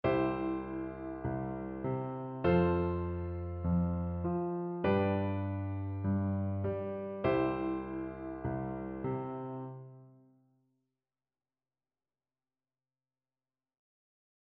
C – F – G – C
Oktaven sind ein beliebtes Mittel, die Bassbegleitung der linken Hand aufzulockern.
Pattern J: Wir oktavieren den Grundton in der linken Hand auf Zählzeit 4
liedbegleitung-pattern-j.mp3